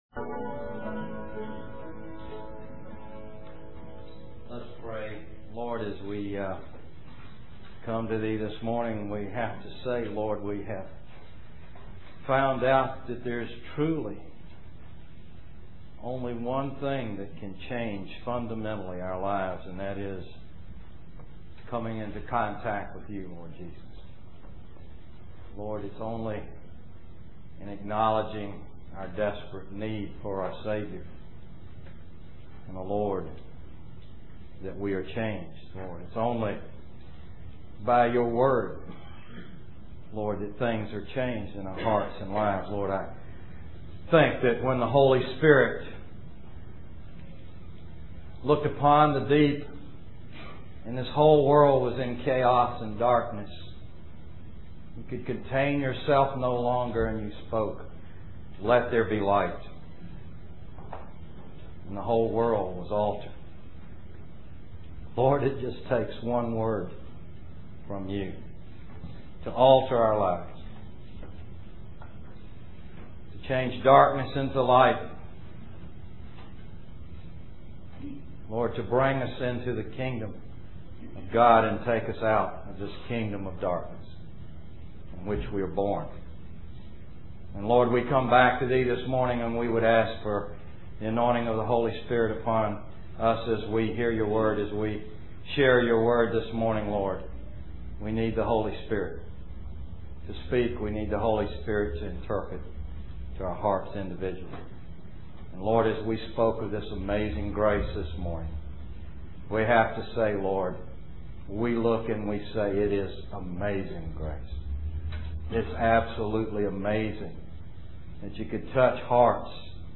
Introduction and prayer